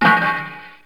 Clank
Clank.wav